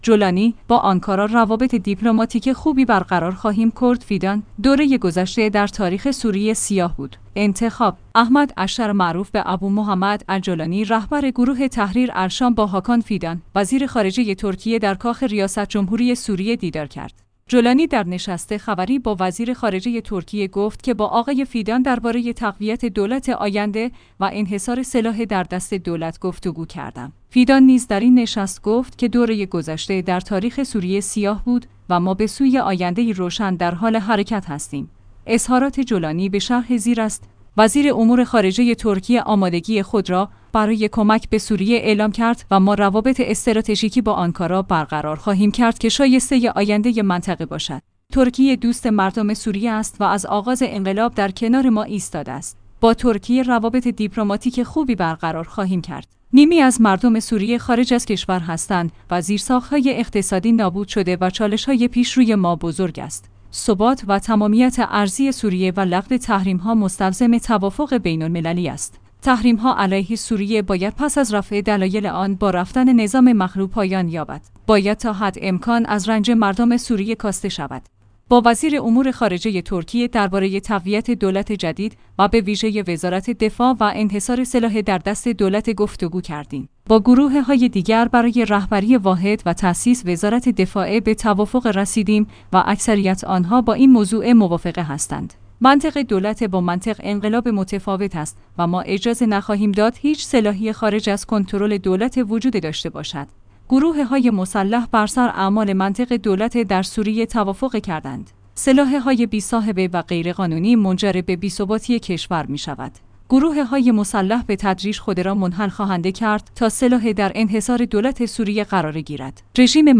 انتخاب/ احمد الشرع معروف به ابومحمد الجولانی رهبر گروه تحریر الشام با هاکان فیدان، وزیر خارجه ترکیه در کاخ ریاست جمهوری سوریه دیدار کرد. جولانی در نشست خبری با وزیر خارجه ترکیه گفت که با آقای فیدان درباره تقویت دولت آینده و انحصار سلاح در دست دولت گفتگو کردم.